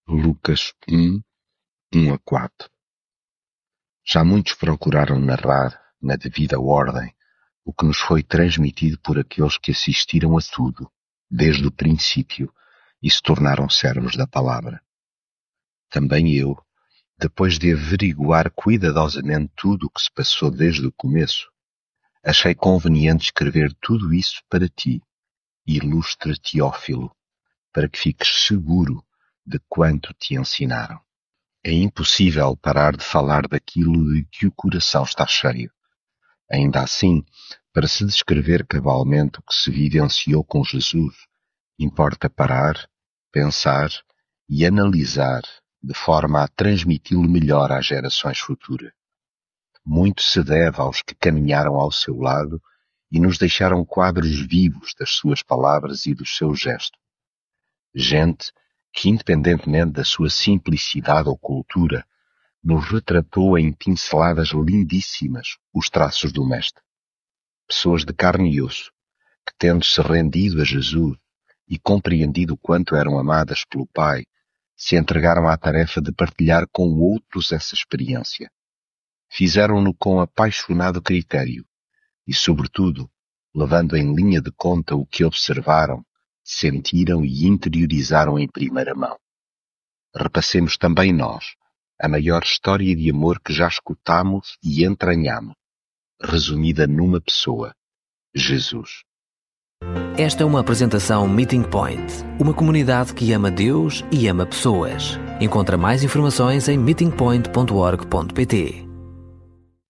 devocional Lucas leitura bíblica Já muitos procuraram narrar, na devida ordem, o que nos foi transmitido por aqueles que assistiram a tudo, desde o princípio,...